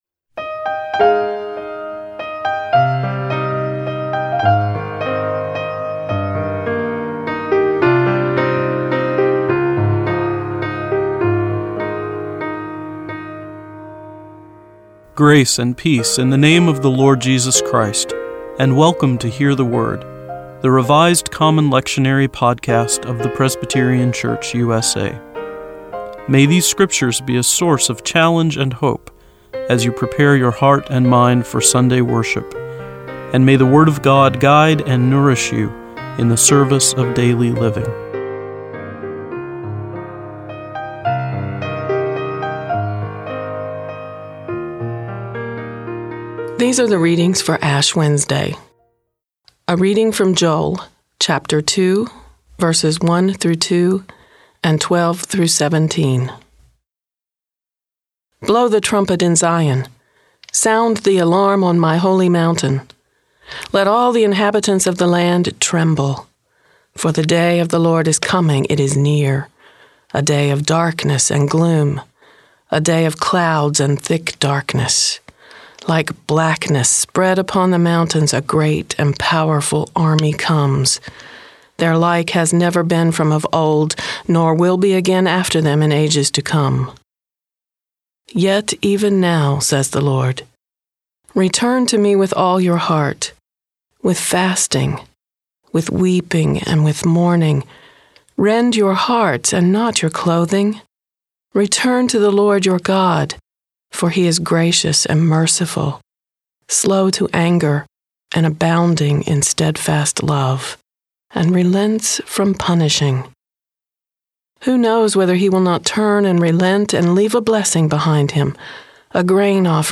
Each podcast (MP3 file) includes four lectionary readings for one of the Sundays or festivals of the church year: an Old Testament reading, a Psalm, an Epistle and a Gospel reading. Following each set of readings is a prayer for the day from the Book of Common Worship.